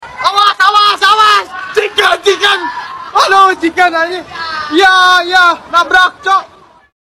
Awas Chicken Sound Effect . sound effects free download